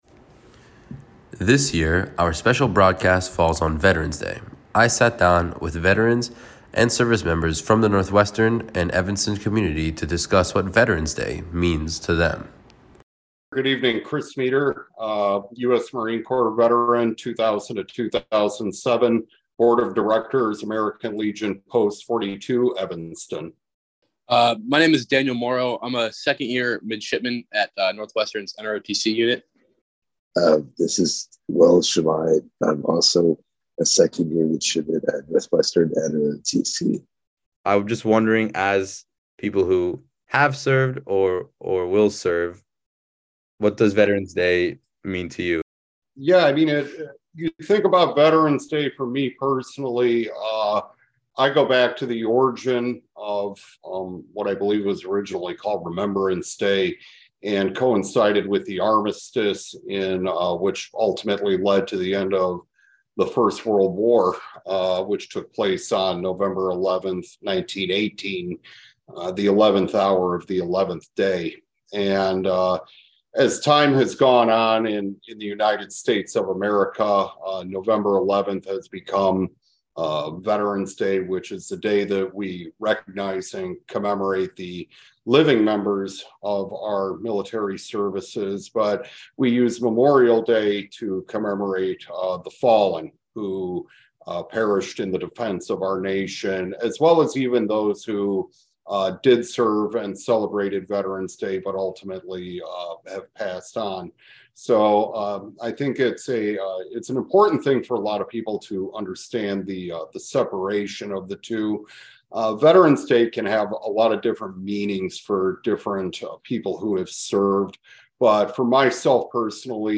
Veteran's Day Roundtable – WNUR News
sat down with local veterans and student members of Northwestern's NROTC unit to discuss what veterans day means to them.